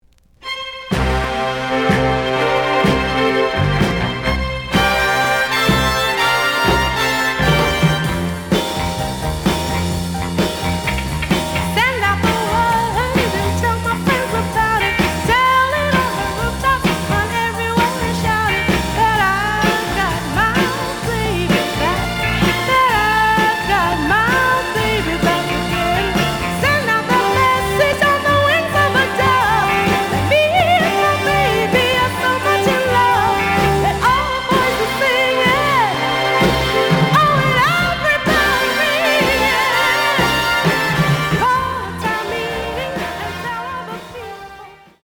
The audio sample is recorded from the actual item.
●Genre: Soul, 60's Soul
Some click noise on beginning of B side.)